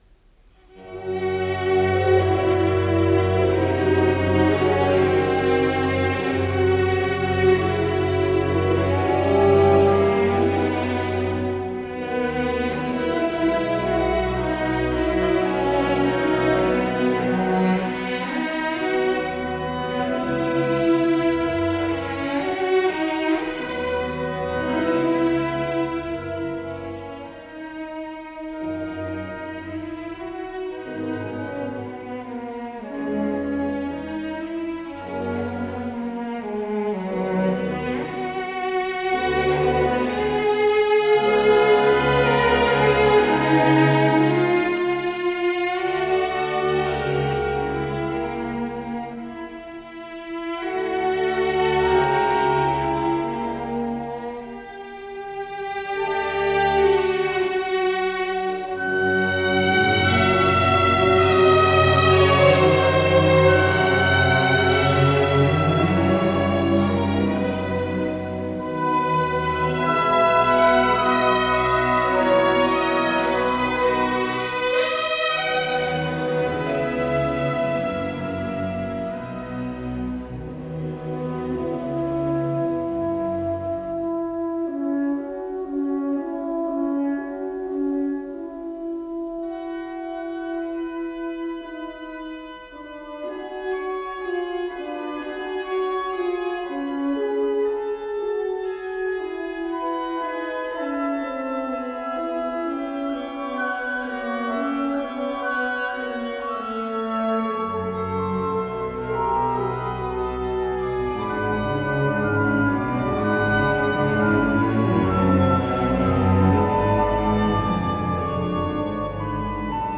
ПодзаголовокСоч. 73. Ре мажор.
ИсполнителиБольшой симфонический оркестр Всесоюзного радио и Центрального телевидения
Дирижёр - Кирилл Кондрашин
Скорость ленты38 см/с
ВариантДубль моно